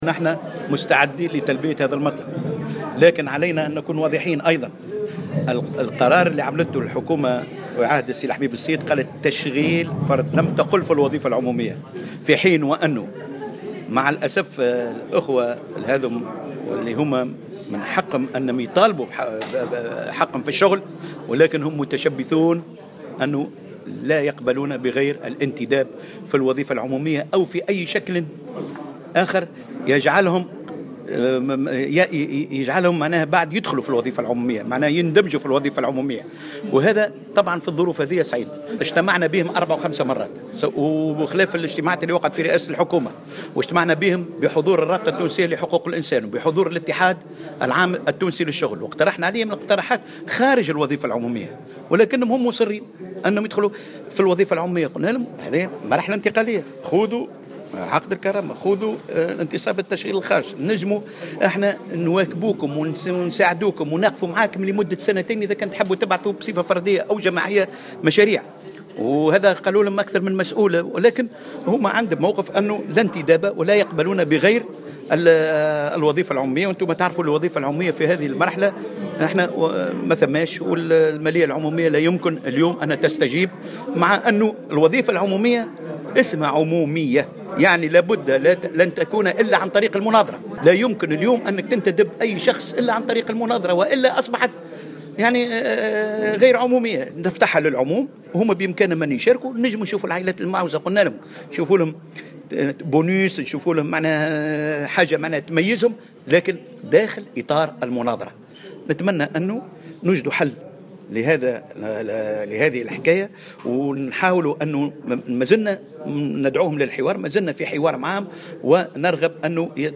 وزير الشؤون الاجتماعية